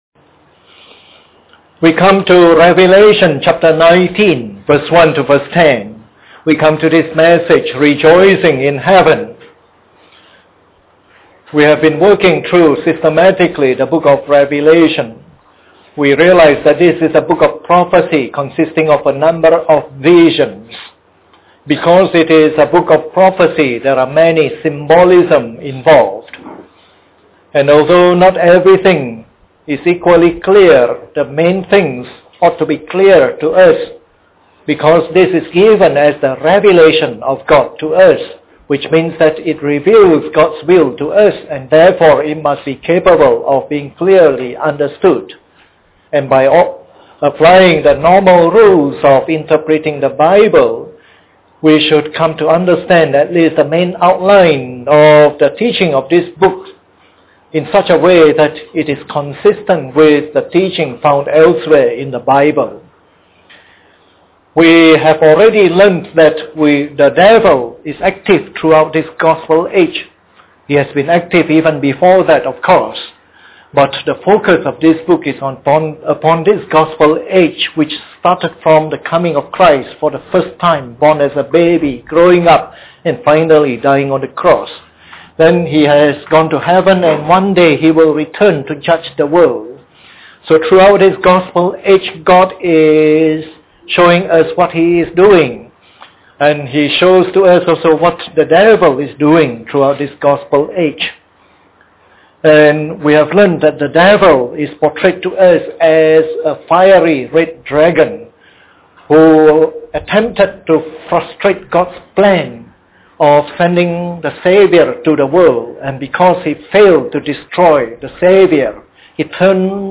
Preached on the 5th of October 2008. This is part of the morning service series on “Revelation”.